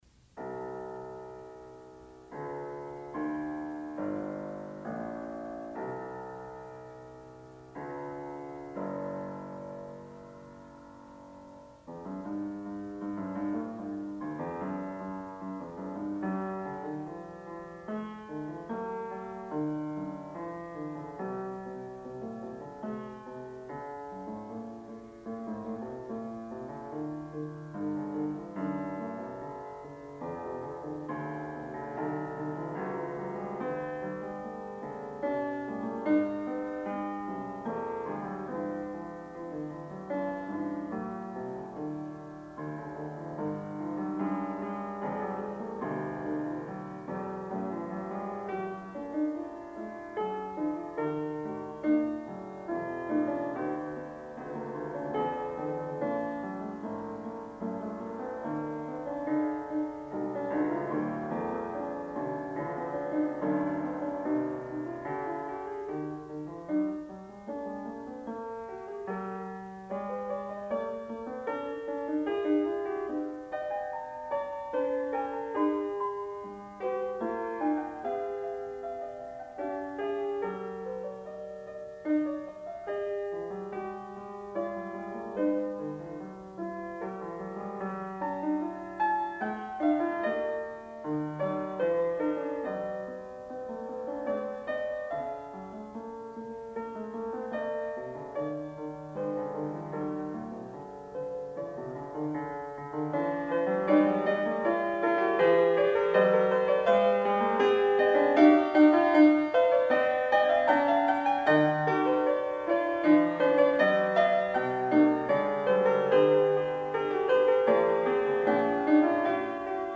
Graduate Recital
06 Fugue.mp3